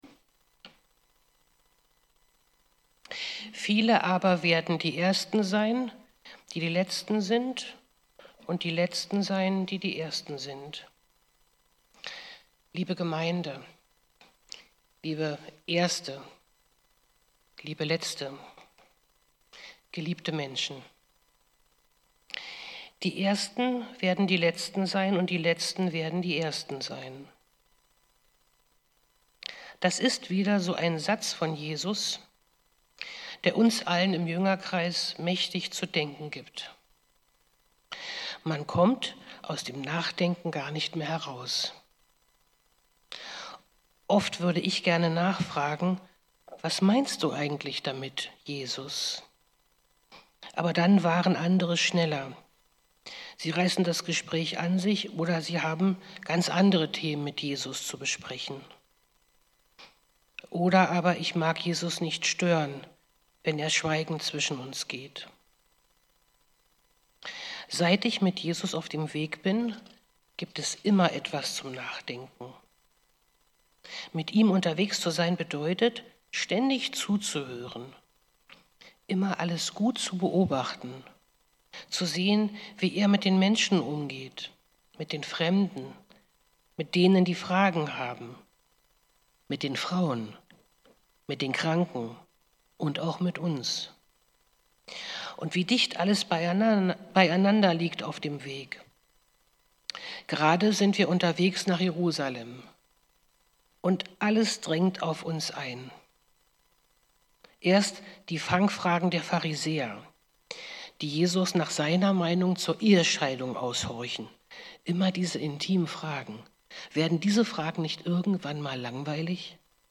Predigten online